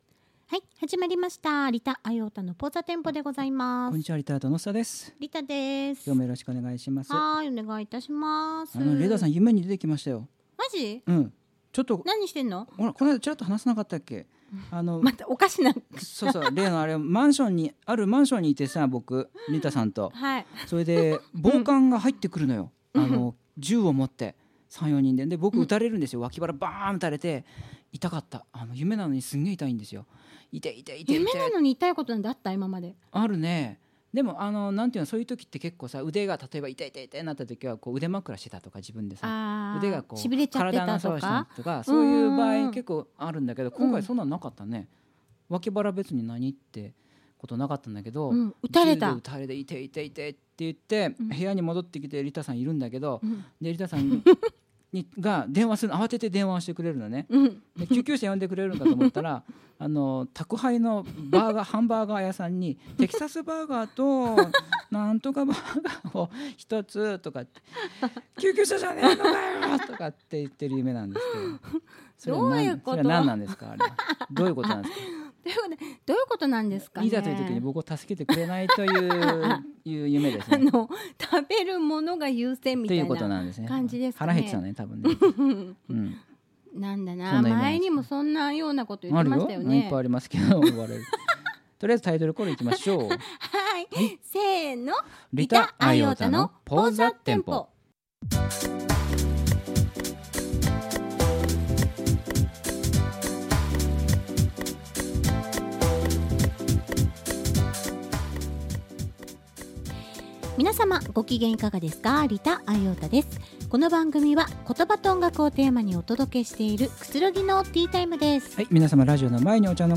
新しいスタジオ初放送！
スタジオ、 新しいし、綺麗だし、天井高いし、静かだし、広いし… 落ち着かないよ〜。笑